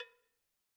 Cowbell1_Hit_v1_rr2_Sum.wav